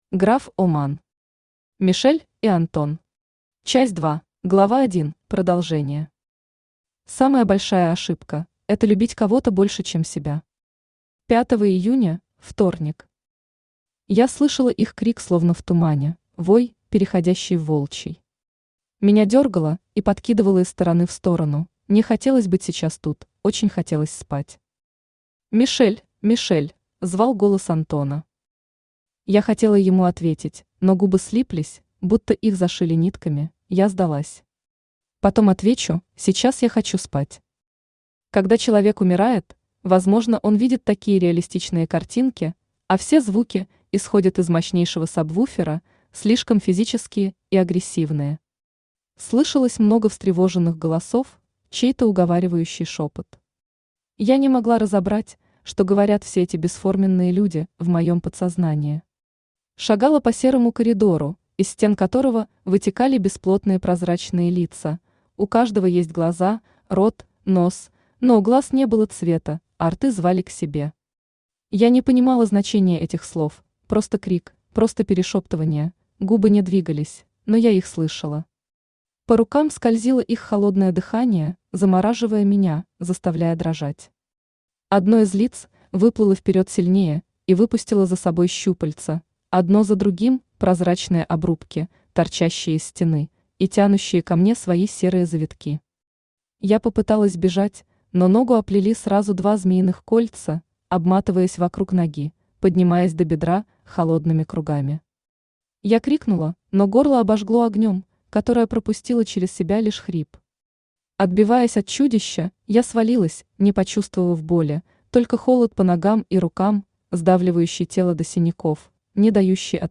Аудиокнига Мишель и Антон. Часть 2 | Библиотека аудиокниг
Часть 2 Автор Граф-О-Ман Читает аудиокнигу Авточтец ЛитРес.